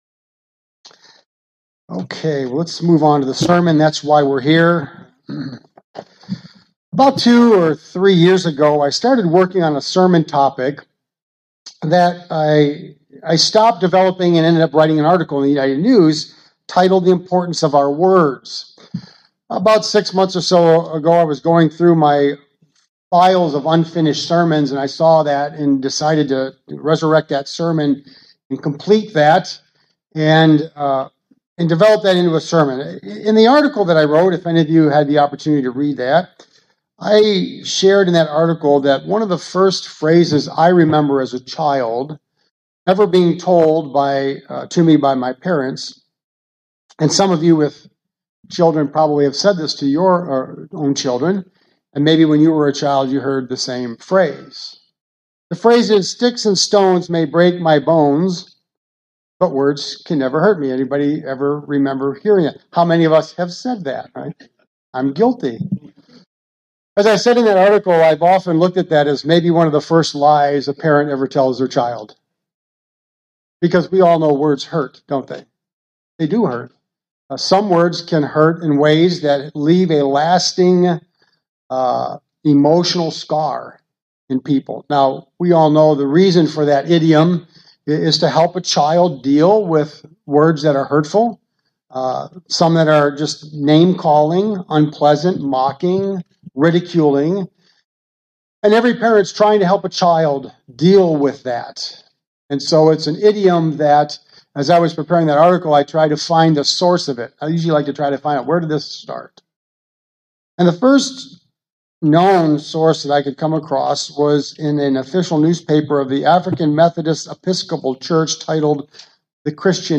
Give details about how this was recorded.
Given in Northwest Indiana